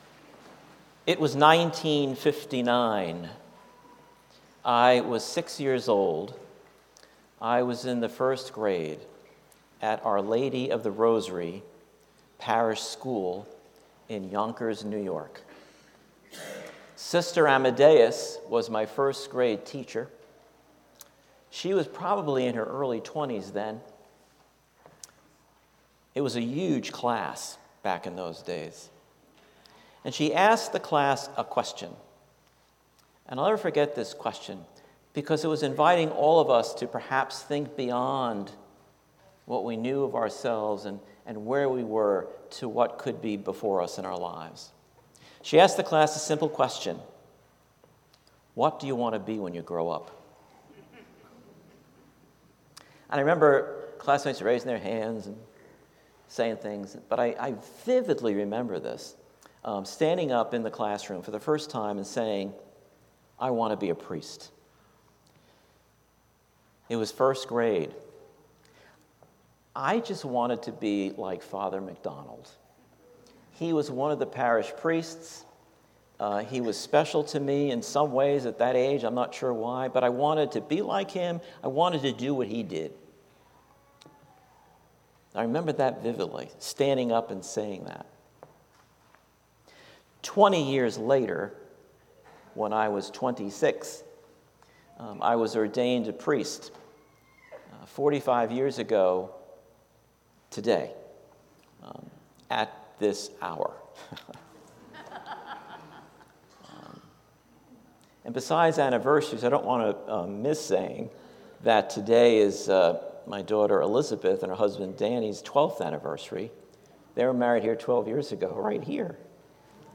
St-Pauls-HEII-9a-Homily-03NOV24.mp3